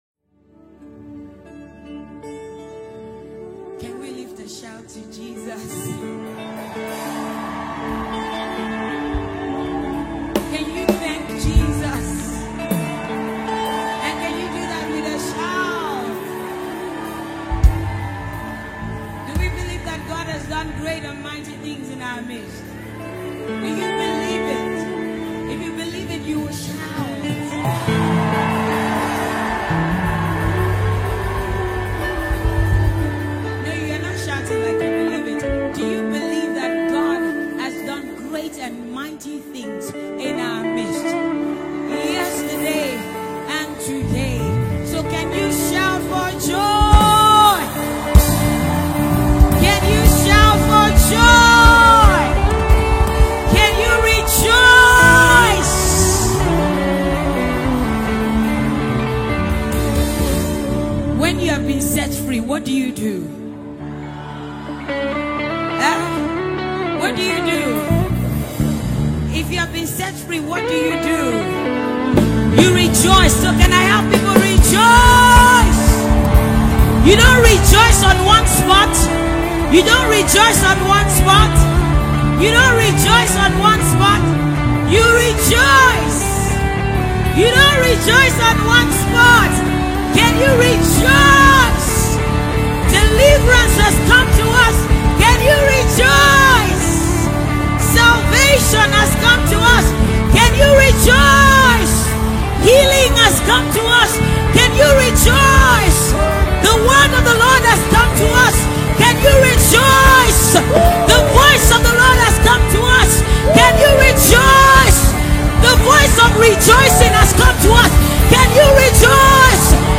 Famous Nigerian gospel singer